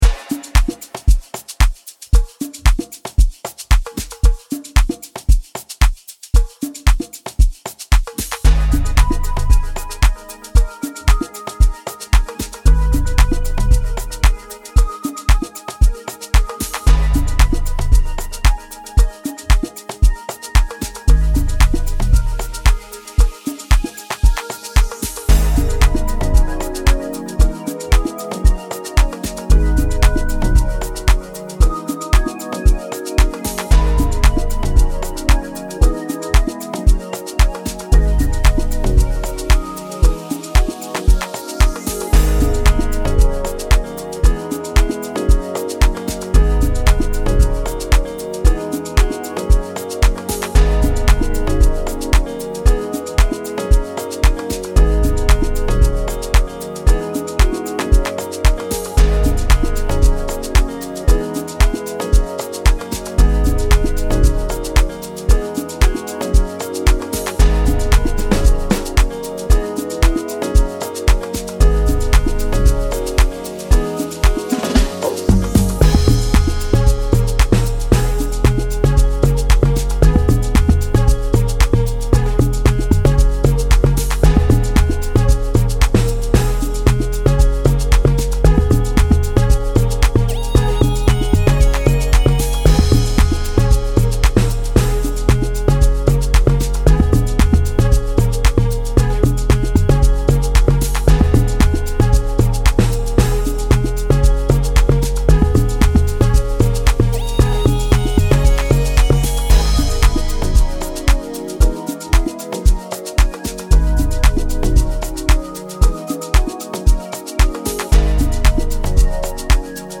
Energetic, Positive, Sexy, Soulful